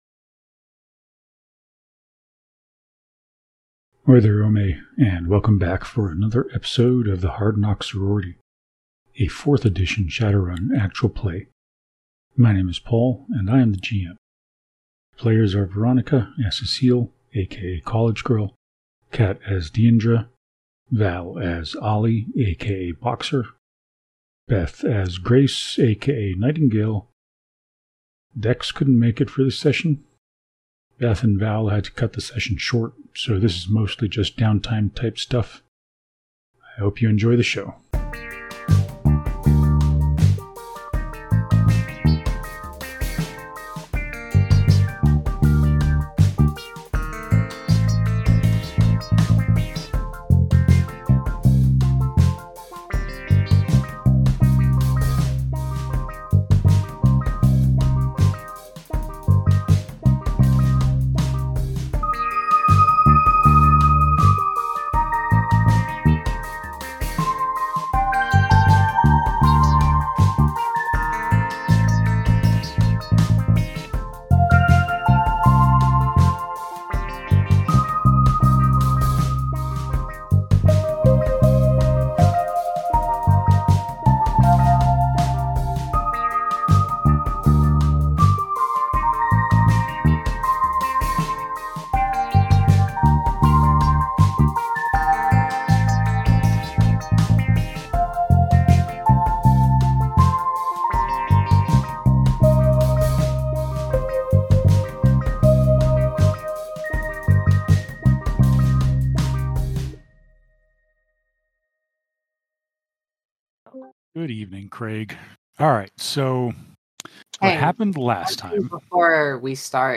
Welcome to the Hard Knocks Sorority PodCast, a Shadowrun 4th Edition Actual Play Podcast.